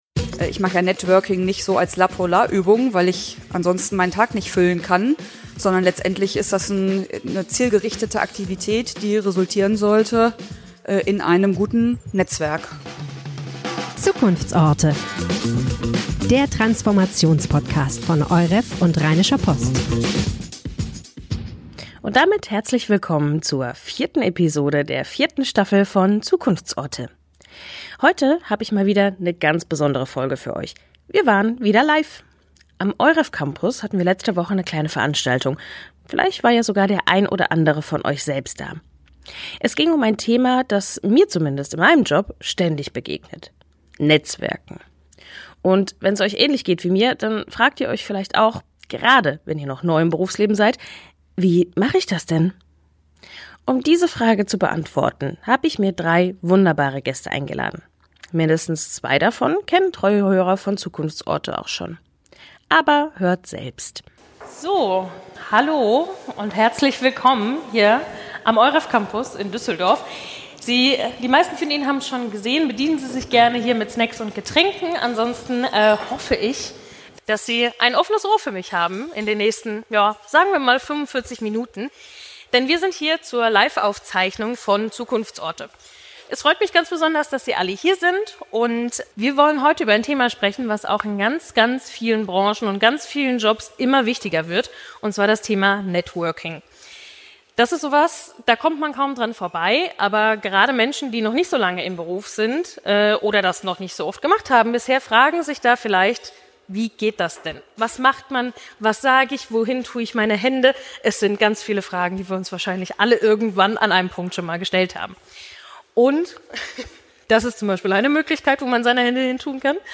LIVE: Energetic Aperitivo goes Düsseldorf - Wie geht eigentlich Networking?
Reicht es, ab und an bei Linked-In zu posten oder muss es doch das regelmäßige Netzwerk-Treffen sein? Drei Gäste im Live-Talk geben Tipps.